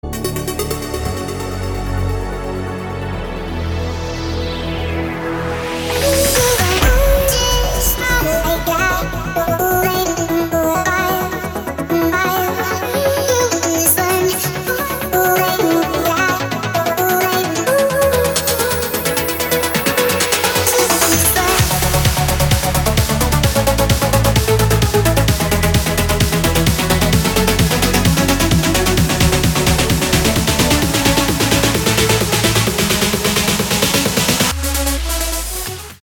• Качество: 320, Stereo
dance
Electronic
club
Trance